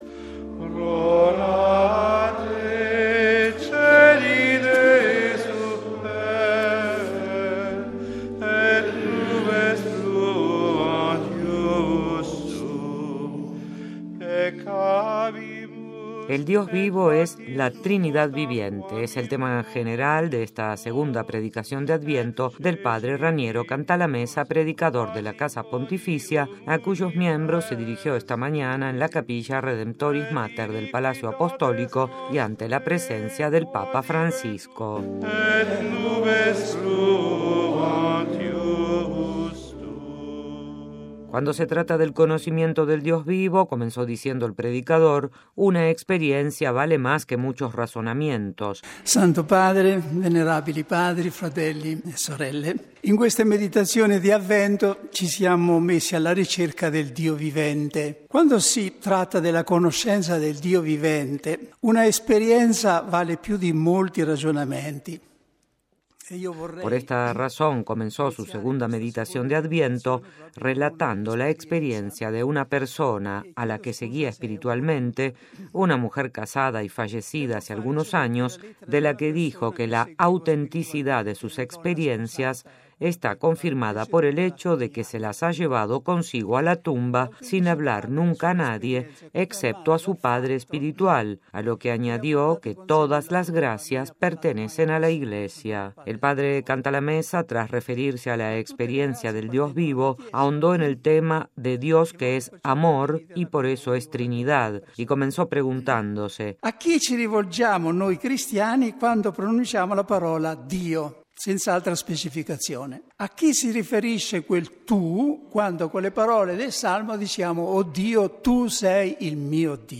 II Predicación de Adviento del P. Cantalamessa: Dios vivo es la Trinidad viviente
Es el tema general de esta segunda predicación de Adviento del Padre Raniero Cantalamessa, predicador de la Casa Pontificia, a cuyos miembros se dirigió esta mañana en la Capilla Redemptoris Mater del Palacio Apostólico y ante la presencia del Papa Francisco